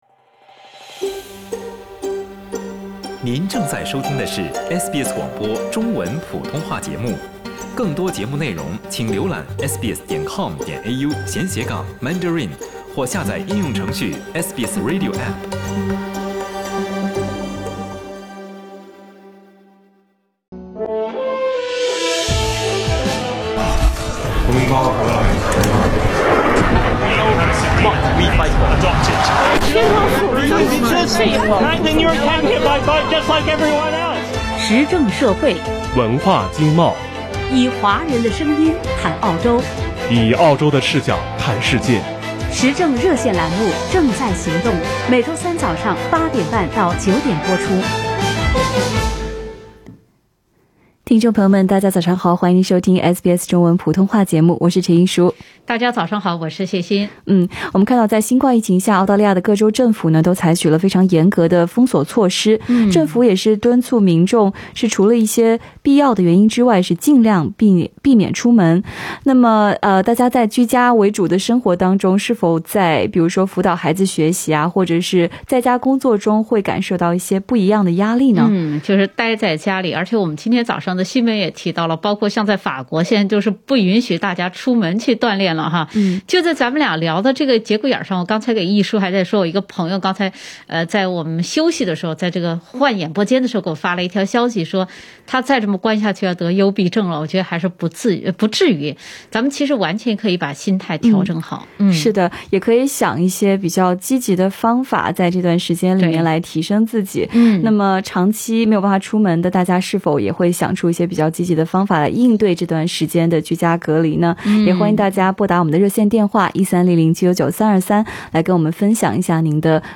疫情之下各国政府采取严格封锁措施，呼吁民众居家隔离。民众居家隔离后，生活上经历了哪些变化,正在行动热线中，听友跟我们分享了自己的积极居家隔离防疫措施。